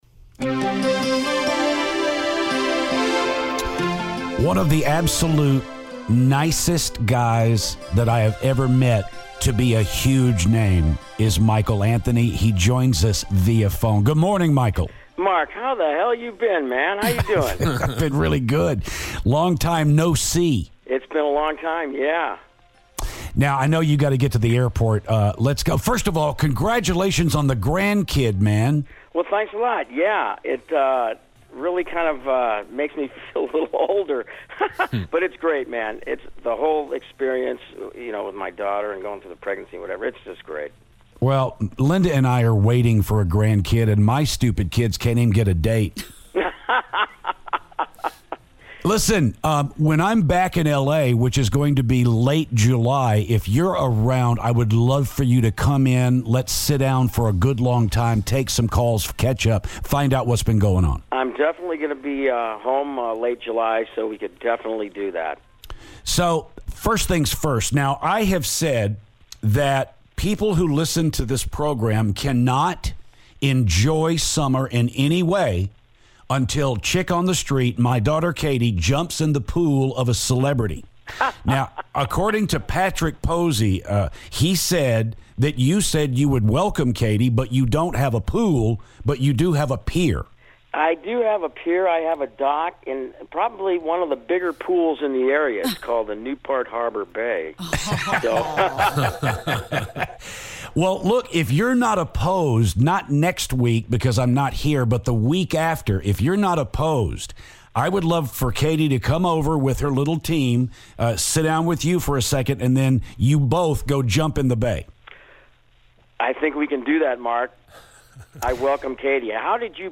Michael Anthony From Van Halen Phoner